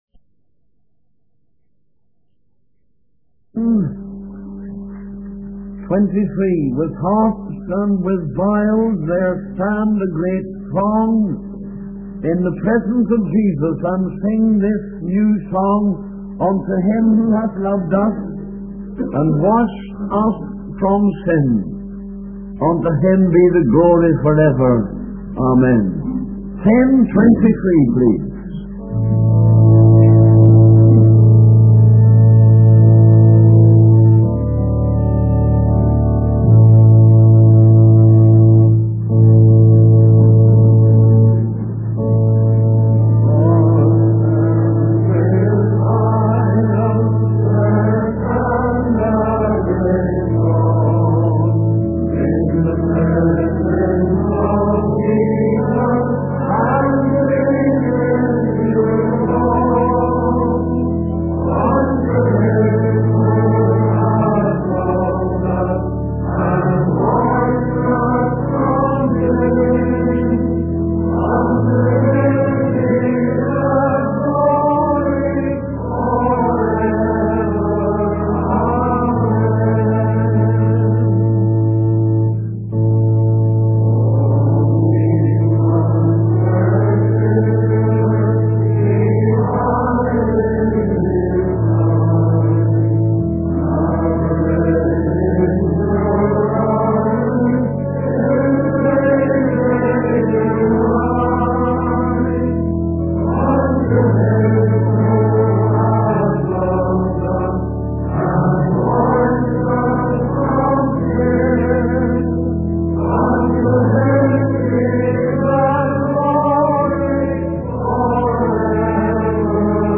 In this sermon, the preacher discusses the opening of the seven seals in the book of Revelation. He emphasizes that these seals represent God's sending of delusion, desperation, starvation, and destruction to the world. The preacher urges the audience to take these warnings seriously and to wake up to the reality of the coming events.